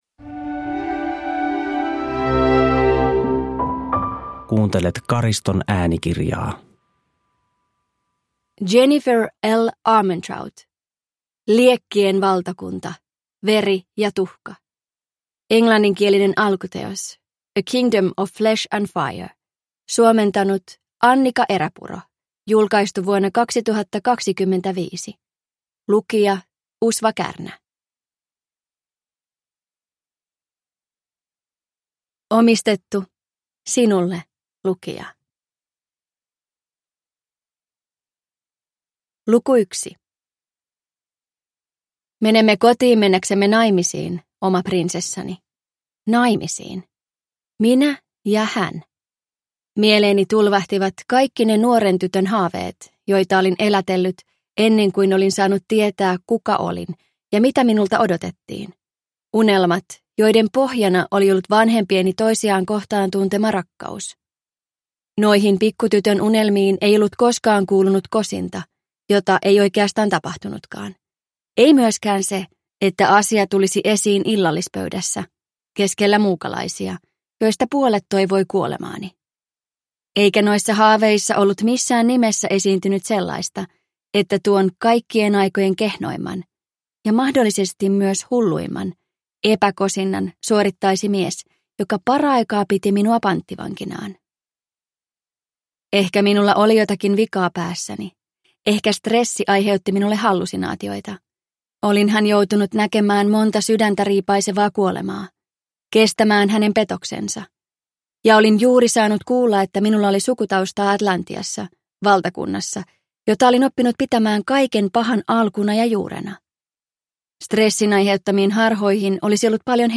Liekkien valtakunta – Ljudbok